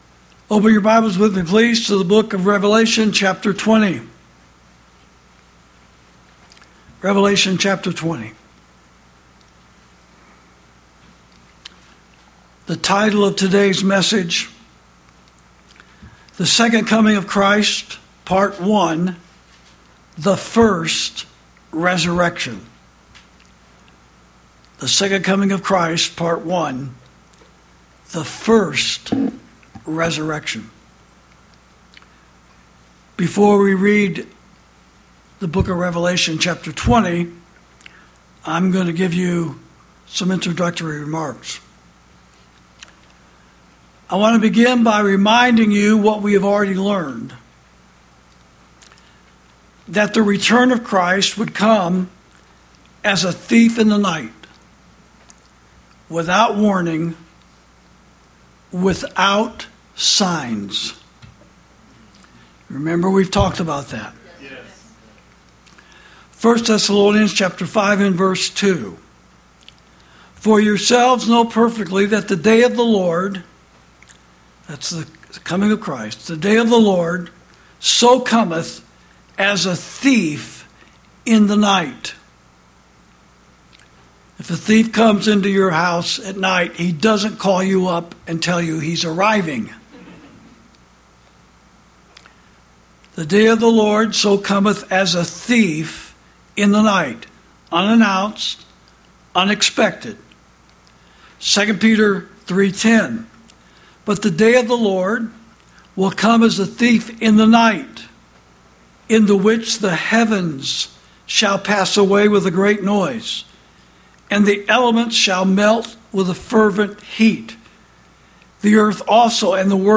Sermons > The Second Coming Of Christ—Part One—The First Resurrection (Prophecy Message Number Twenty-Five)